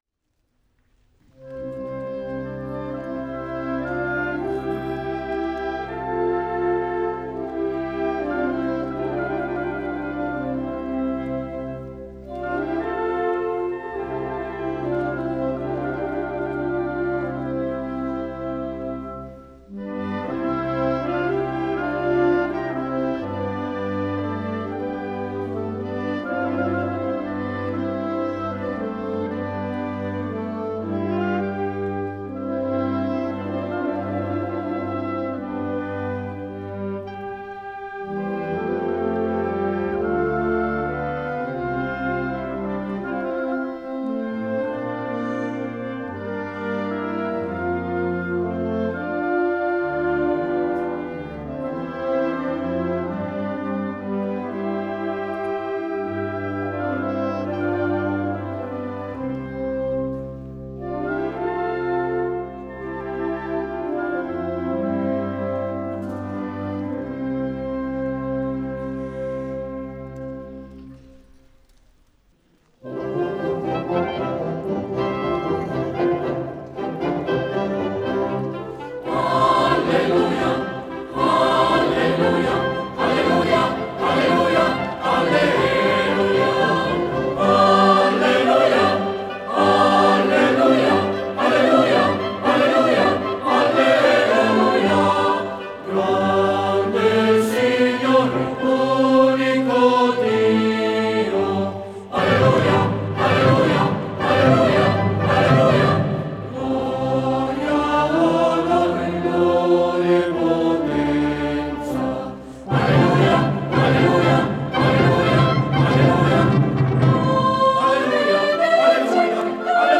Per Coro 4 voci e Banda